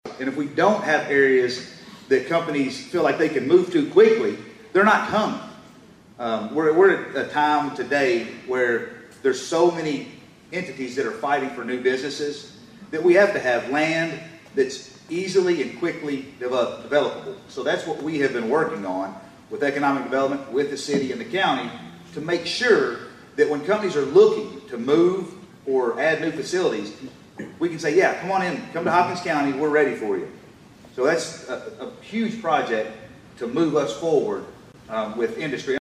At the State of the Cities and County Address last week